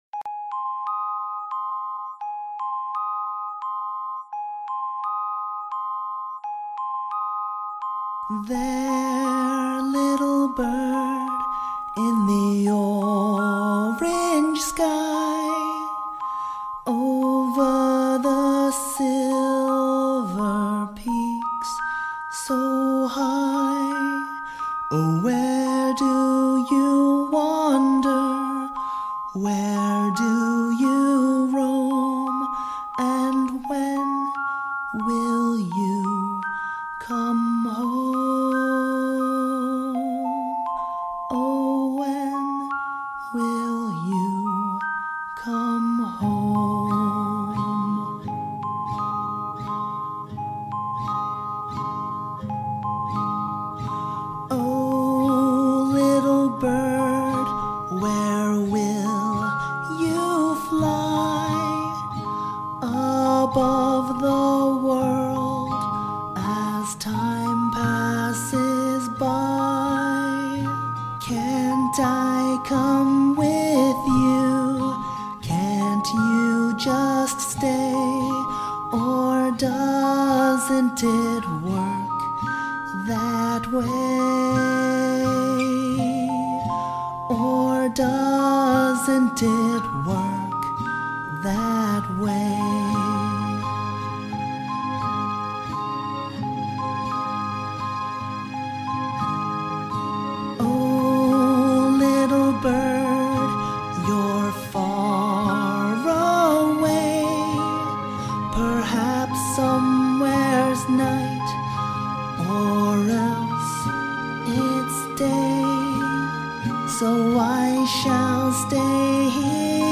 A vocal cover